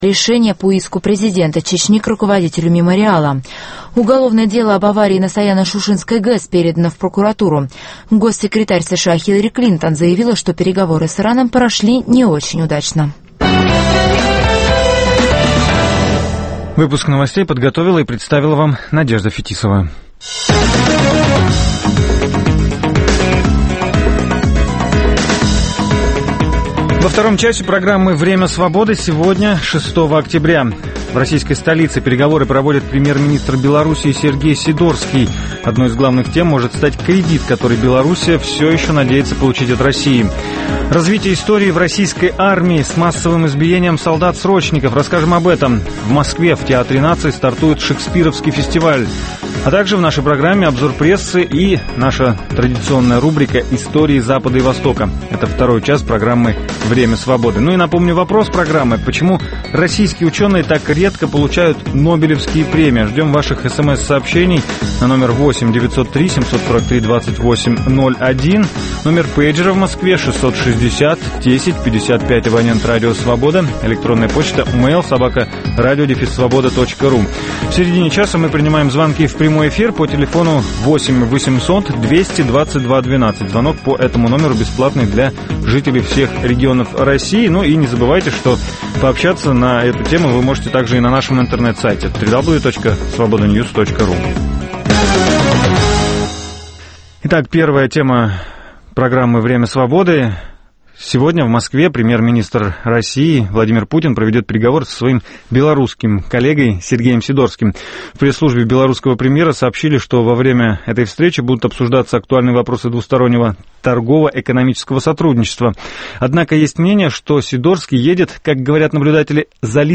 С 9 до 10 часов утра мы расскажем о том, что готовит нам начинающийся день. Представим панораму политических, спортивных, научных новостей, в прямом эфире обсудим с гостями и экспертами самые свежие темы нового дня, поговорим о жизни двух российских столиц.